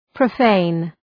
Προφορά
{prə’feın}